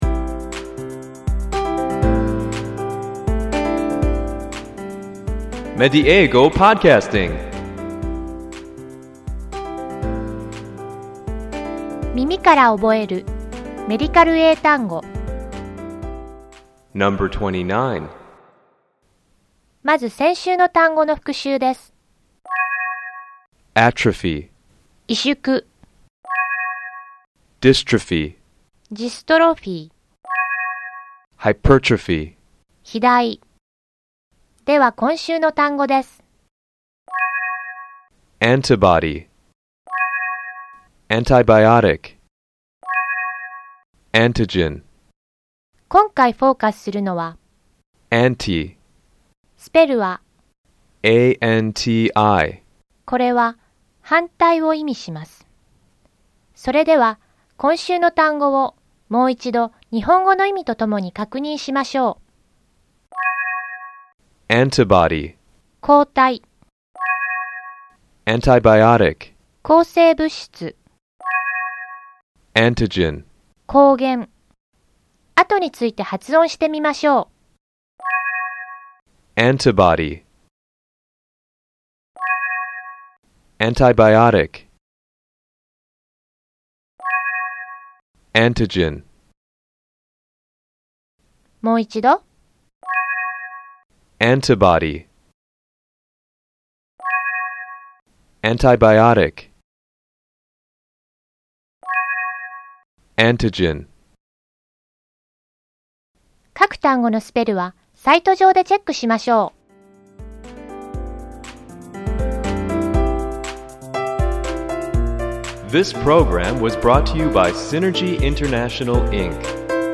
この「耳から覚えるメディカル英単語」では，同じ語源を持つ単語を毎週3つずつ紹介していきます。ネイティブの発音を聞いて，何度も声に出して覚えましょう。